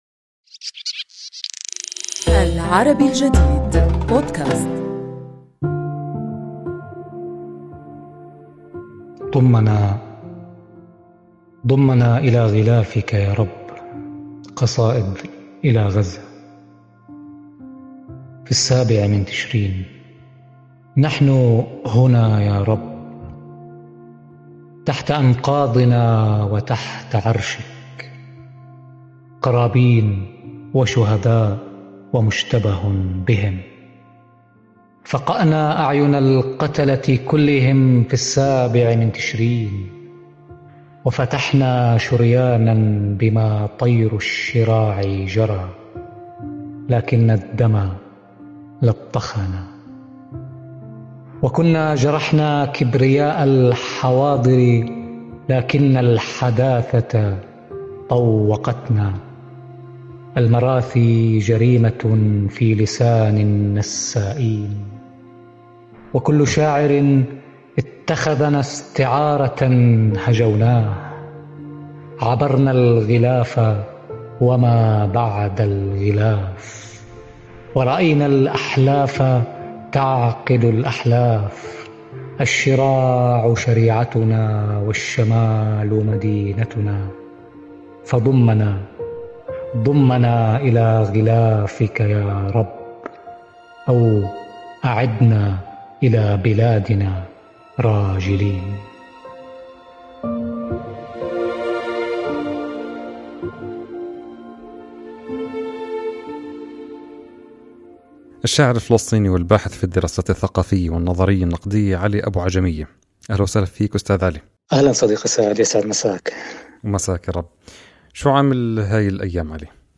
حوارُنا اليوم